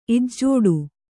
♪ ijjōḍu